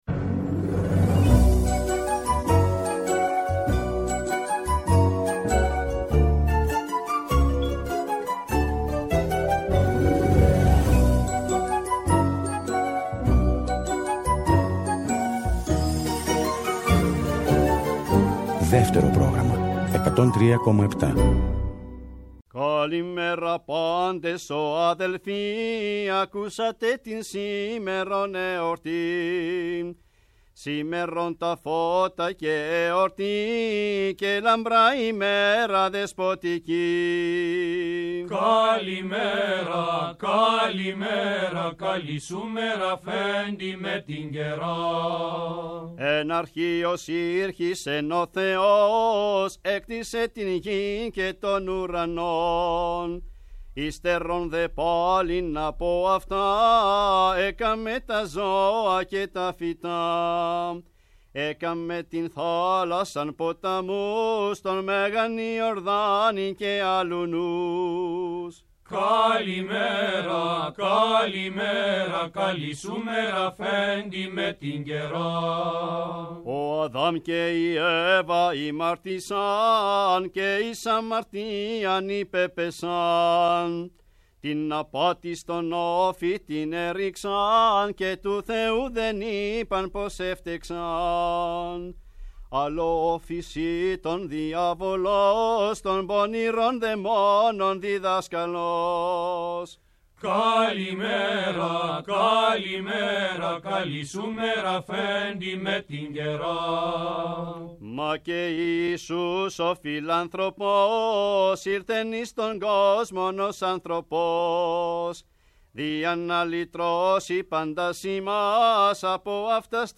Ηχογραφήσεις που έγιναν στην Ελληνική Ραδιοφωνία στα χρόνια του ’50 και του ’60, με γυναικείες φωνές των Δωδεκανήσων.
Συρτά και καλαματιανά, τραγούδια της θάλασσας, της ξενιτιάς και του γάμου
στο βιολί
στο κανονάκι
στο σαντούρι. Κι ανάμεσα στις ηχογραφήσεις, αφηγήσεις – μνήμες των τραγουδιστριών από την εποχή και τις ηχογραφήσεις.